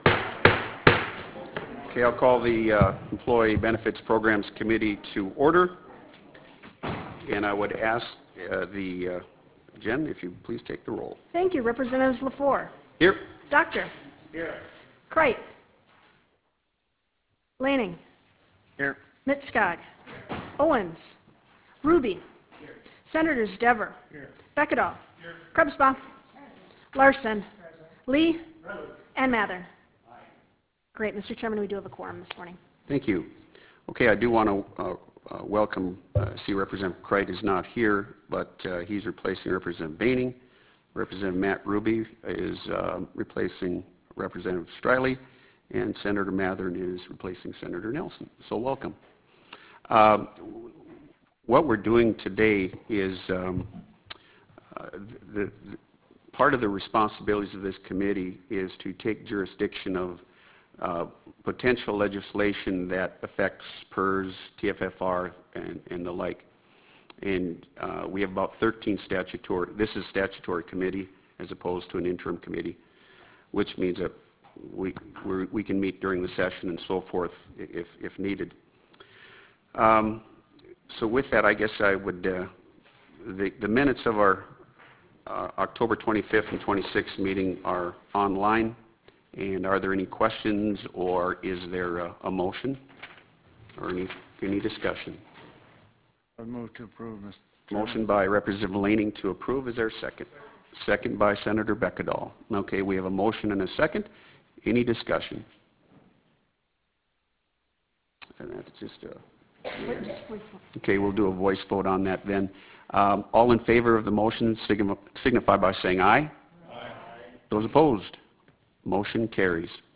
Prairie Room State Capitol Bismarck, ND United States